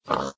sounds / mob / pig / say1.ogg